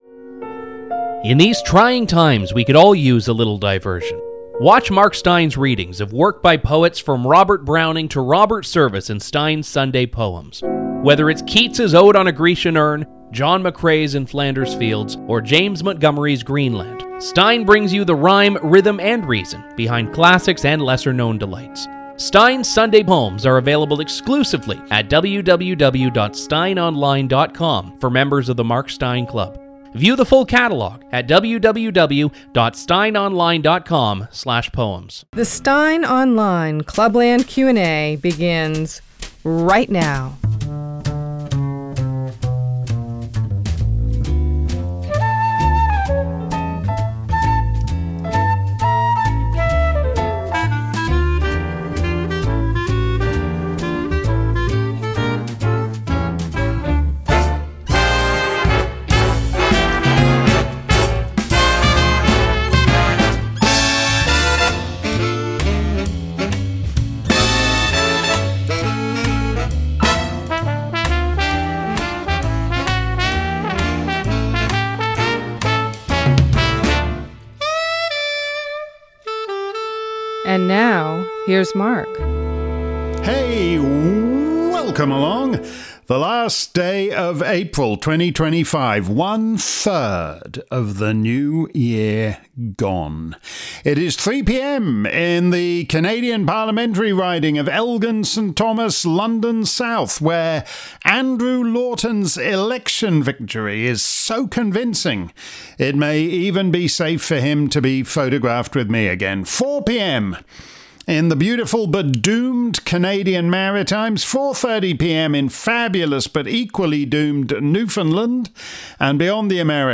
If you missed today's edition of Steyn's Clubland Q&A live around the planet, here's the action replay. This week's show covered a range of topics from the Great Euro-Blackout to the name of Mark's paramilitary organisation.